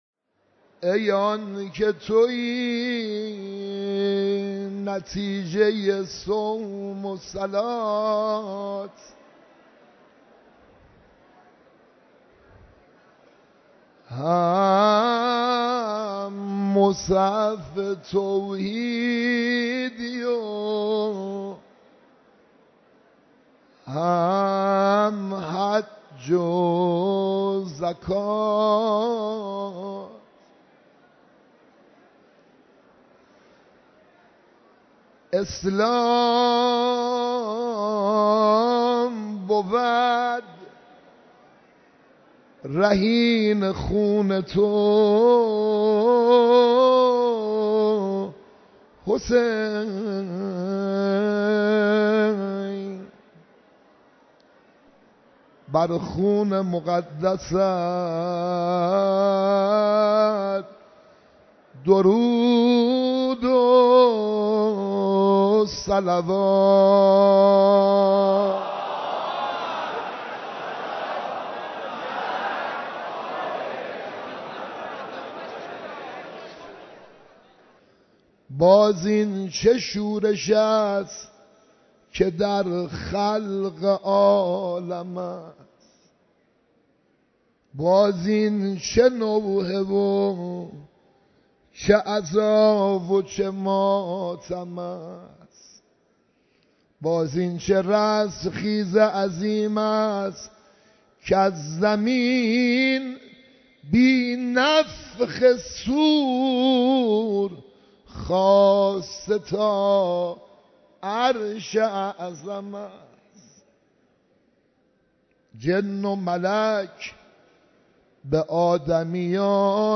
مراسم عزاداری شب عاشورای حسینی(ع)
مراسم عزاداری شب عاشورای حسینی علیه‌السلام برگزار شد
مداحی